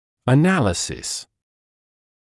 [ə’næləsɪs][э’нэлэсис]анализ (мн.ч. analyses [ə’nælɪsiːz])